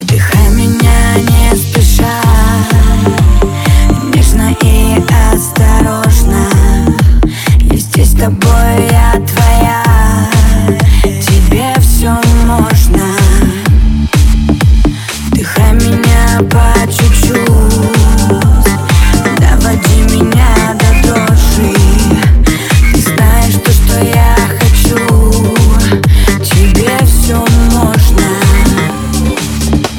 • Качество: 160, Stereo
поп
remix
dance
club
чувственные